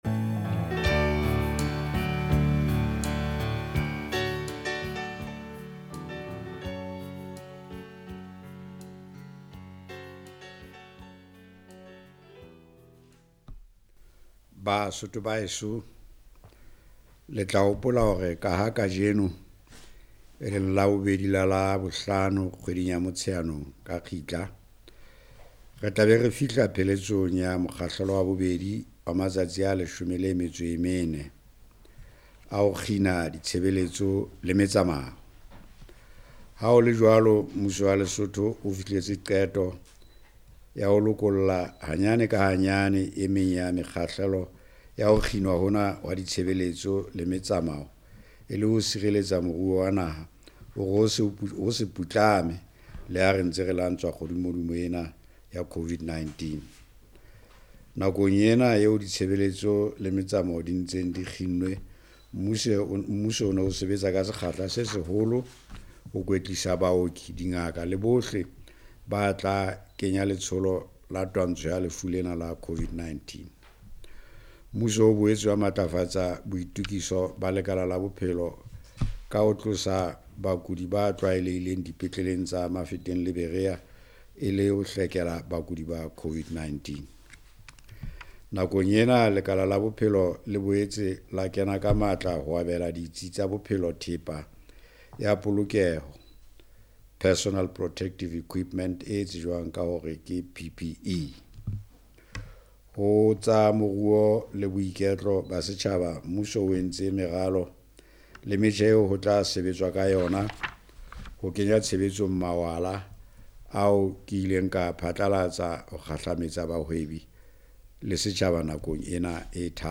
The Department of Traffic in the Ministry of Transport has opened to provide services to truck drivers considered offering essential services. The Principal Secretary in the Ministry of Transport, Mr. Thabo Motoko said this in an interview with LENA on Monday.